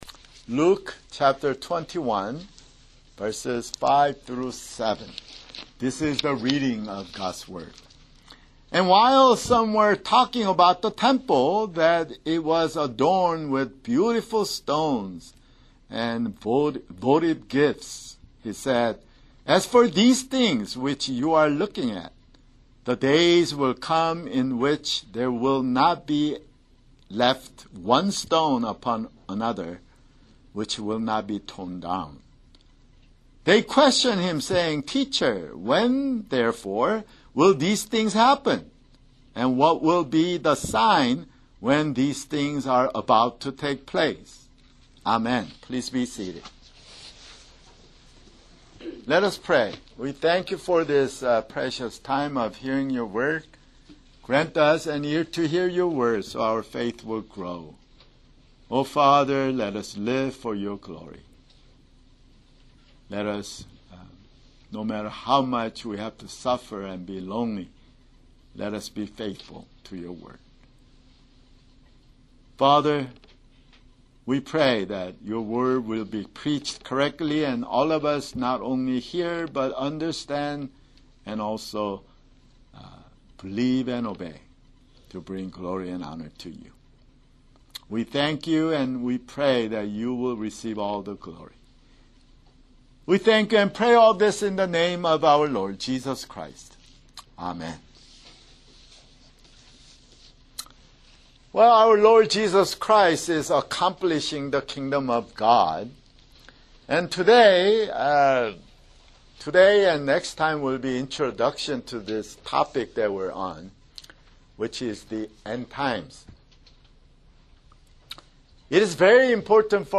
[Sermon] Luke (138)